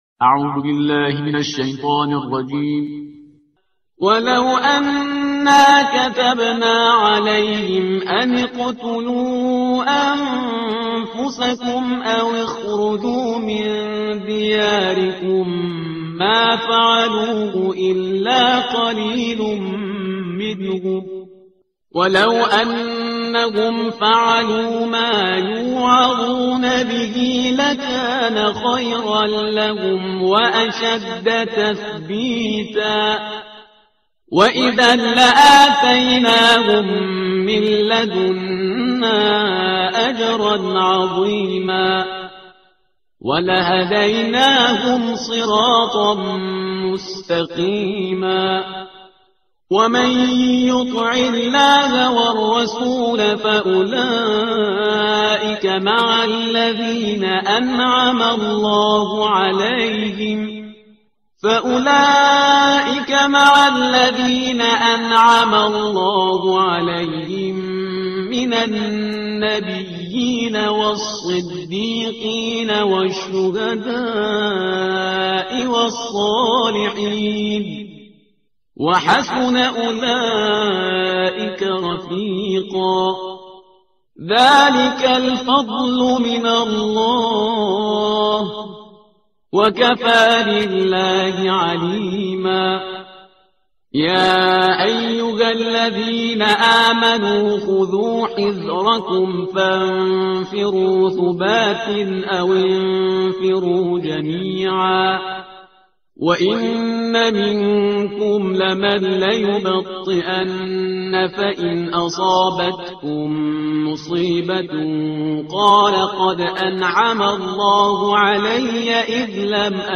ترتیل صفحه 89 قرآن با صدای شهریار پرهیزگار